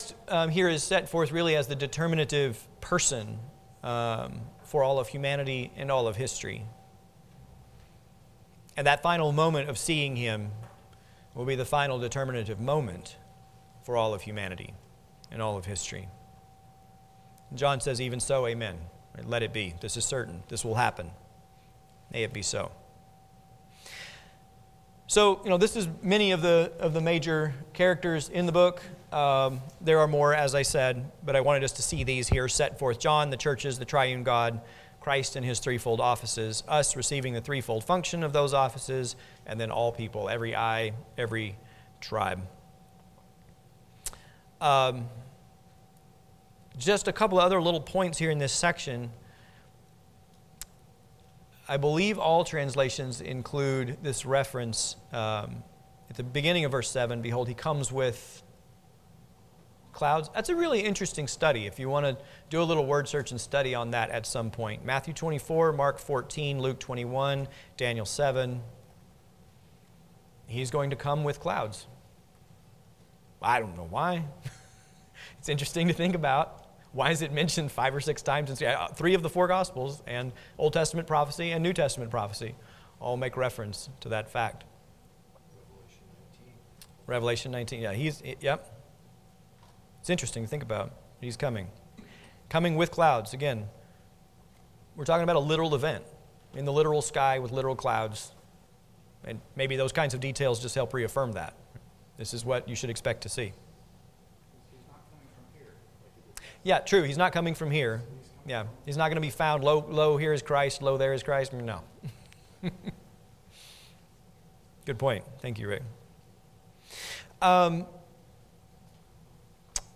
Passage: Revelation 1 Service Type: Sunday School